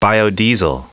E a pronúncia correta em inglês seria: "-'dE-z&l, -s&l"
ouça aqui a pronúncia em inglês.